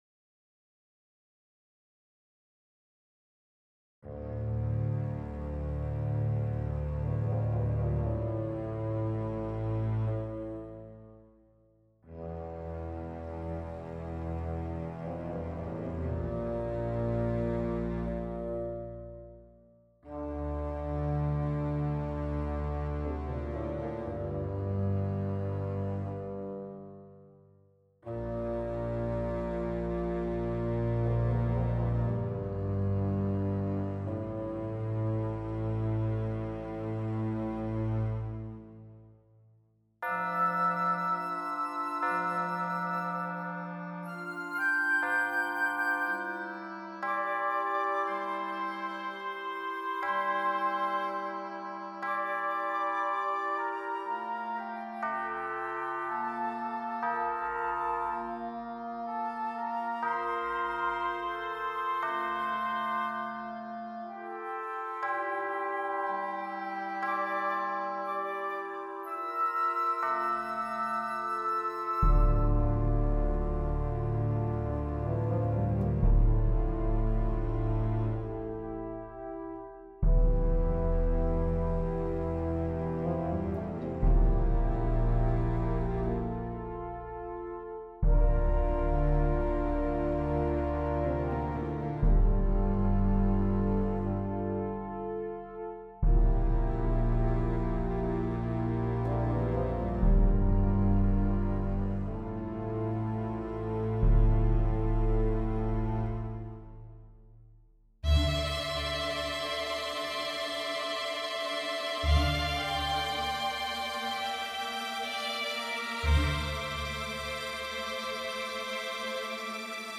As the clock strikes twelve on All Hallows’ Eve, a spectral symphony unfurls. The music begins with a whisper—creaking doors, rustling leaves, distant howls—then erupts into a cacophony of gleeful chaos as ghouls, goblins, and shadowy tricksters flood the night.